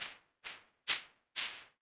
Piano 08_hpsModel_stochastic
attack hps hpsModel keyboard keys piano played sinusoidal sound effect free sound royalty free Memes